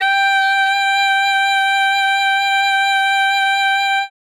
42c-sax11-g5.wav